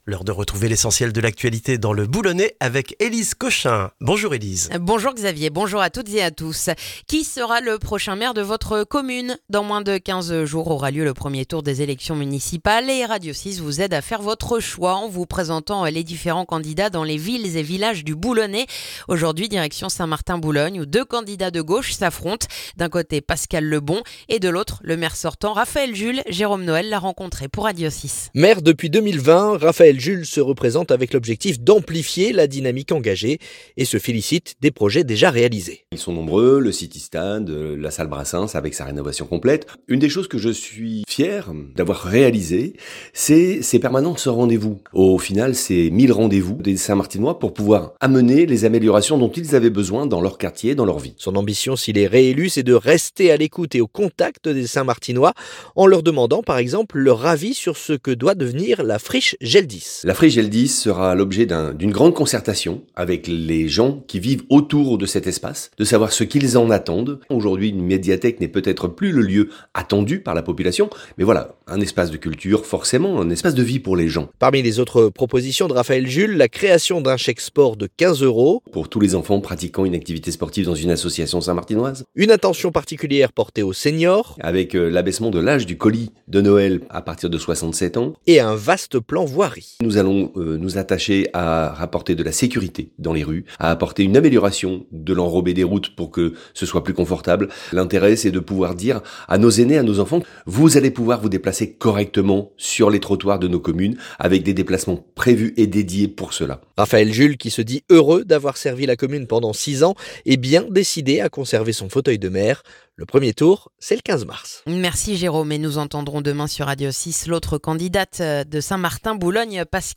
Le journal du lundi 2 mars dans le boulonnais